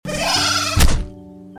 Sheepy Jumpscare Sound Button - Free Download & Play